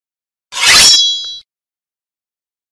Suara Pedang Samurai
Kategori: Suara senjata tempur
Keterangan: Suara pedang samurai saat ditebaskan, cocok untuk efek suara meme, edit video, dan konten viral. Download sekarang untuk menambahkan nuansa aksi pada video Anda dengan suara yang tajam dan autentik dari tebasan pedang samurai.
suara-pedang-samurai-id-www_tiengdong_com.mp3